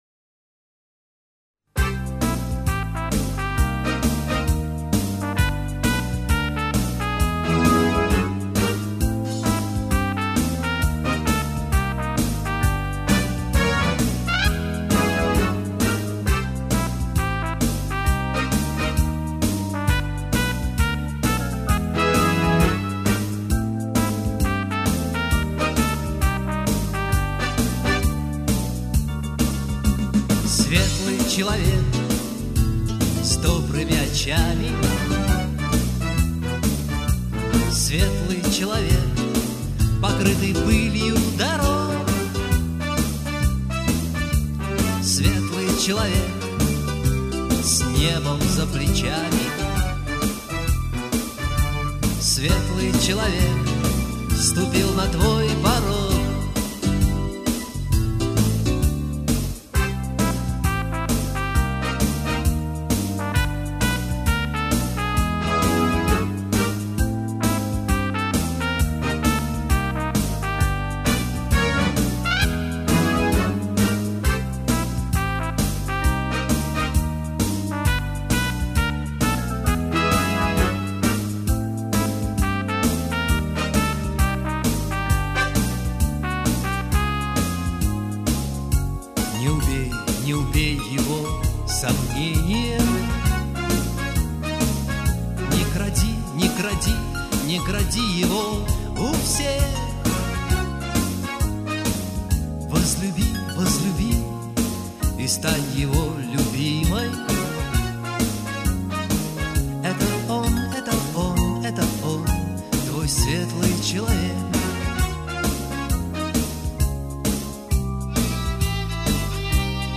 Made in Monday-City (Dushanbe).